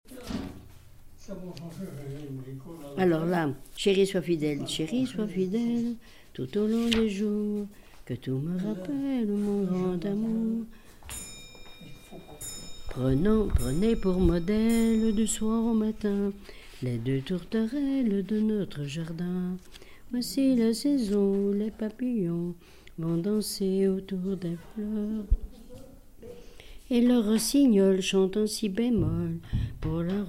témoignage et chansons
Pièce musicale inédite